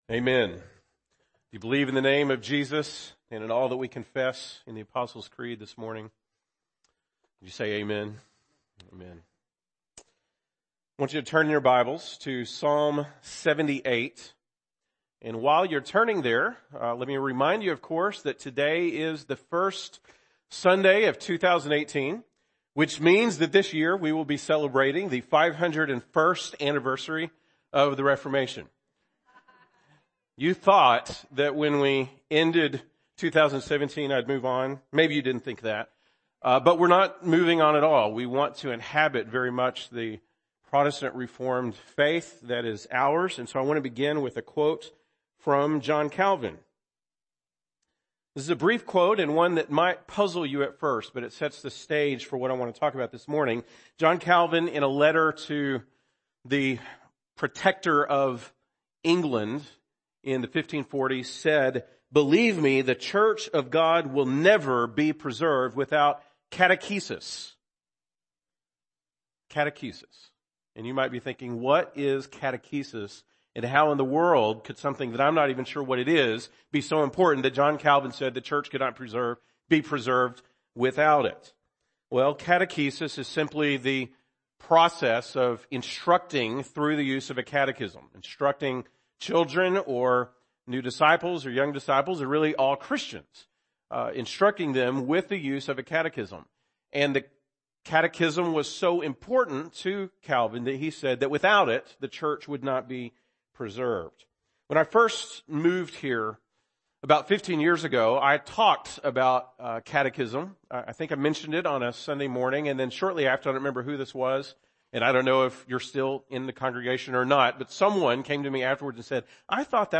January 7, 2018 (Sunday Morning)